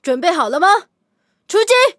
LT-35出击语音.OGG